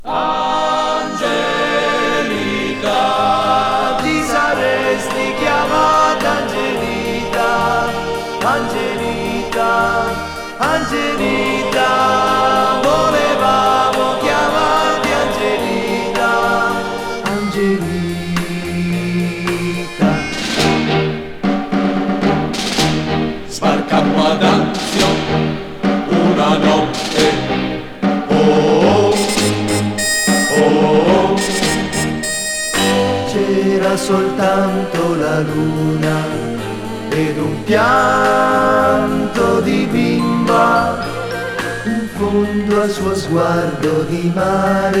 Pop, Vocal　USA　12inchレコード　33rpm　Mono